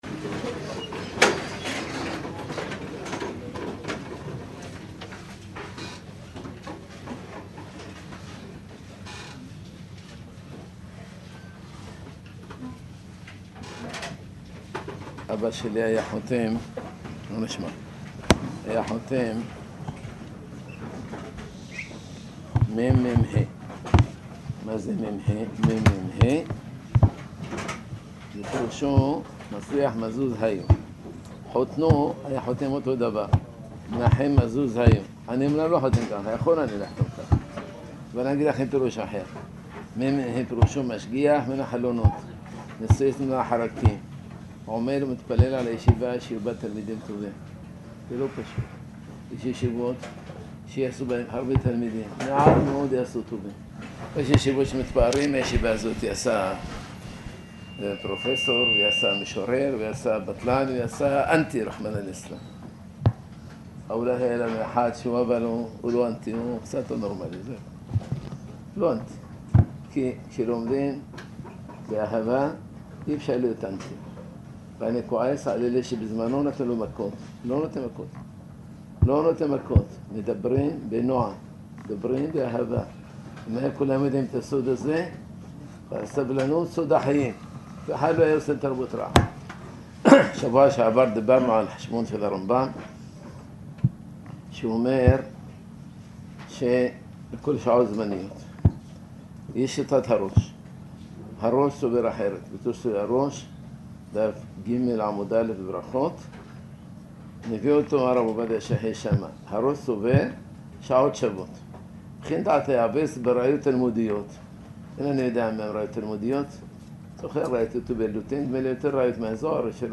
שעור בהלכה בעיון